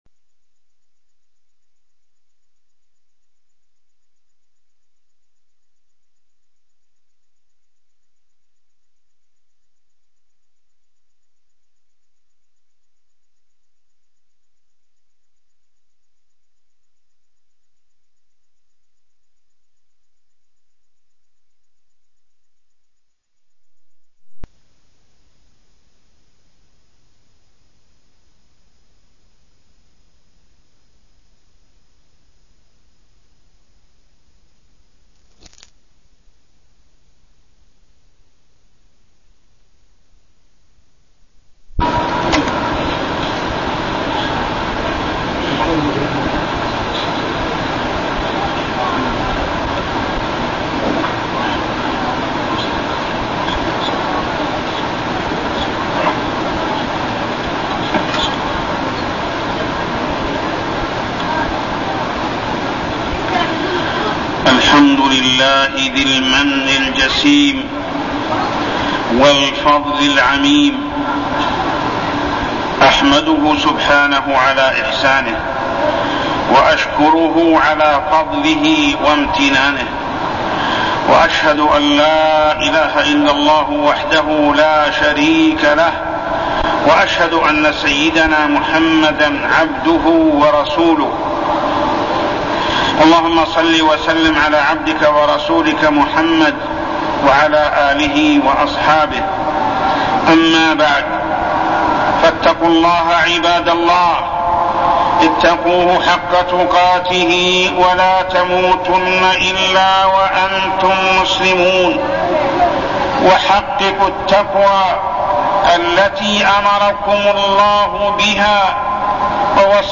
تاريخ النشر ٢ ذو الحجة ١٤١١ هـ المكان: المسجد الحرام الشيخ: محمد بن عبد الله السبيل محمد بن عبد الله السبيل صفة حج النبي صلى الله عليه وسلم